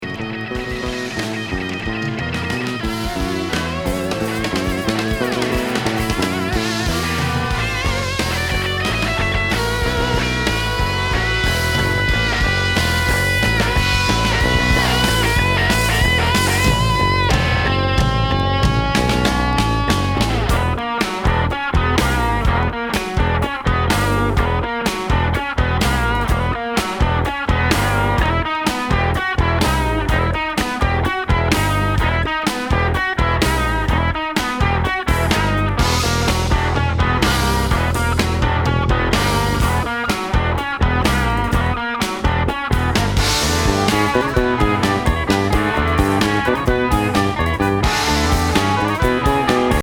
サイケ・ニューエイジなギター